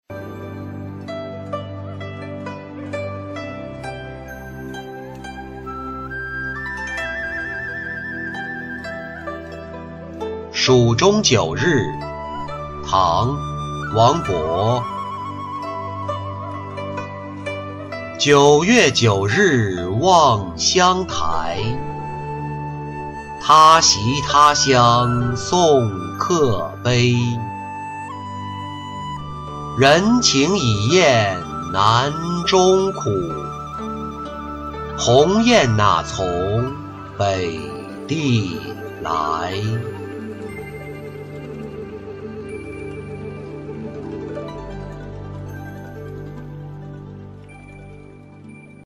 蜀中九日-音频朗读